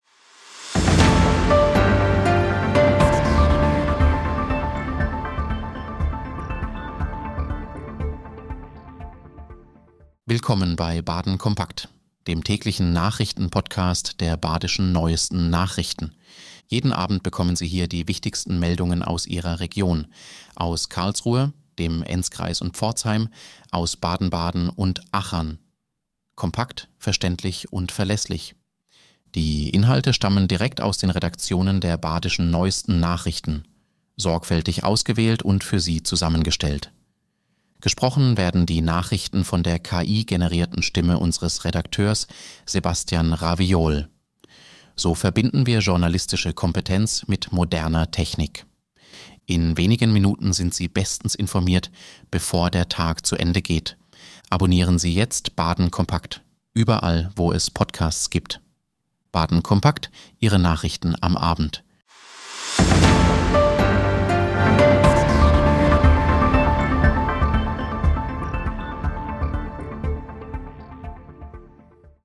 Nachrichten
KI-generierten Stimme